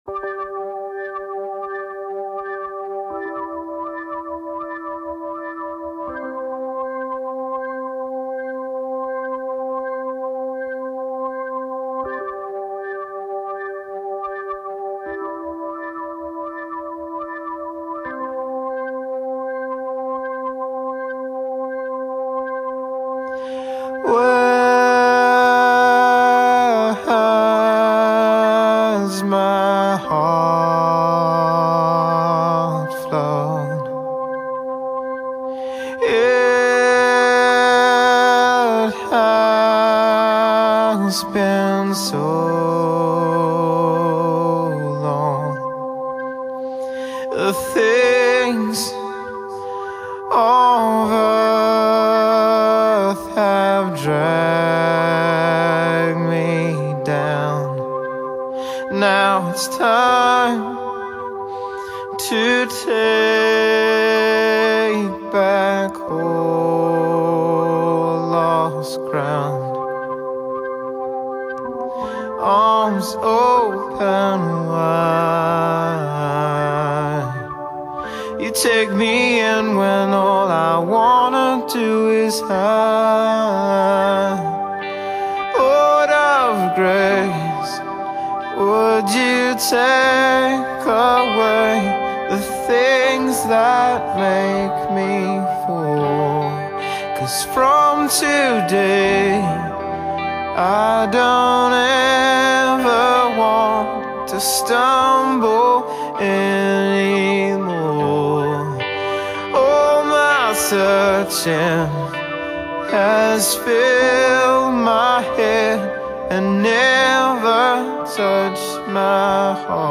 devotional
worship music